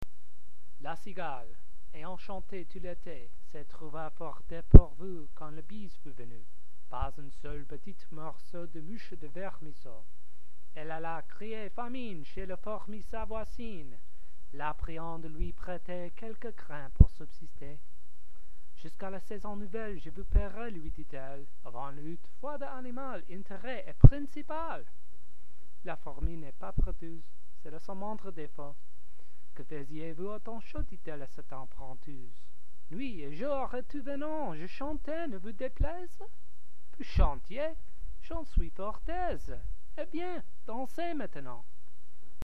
Non-native speaker
Accent: american
my attempt at a standard french accent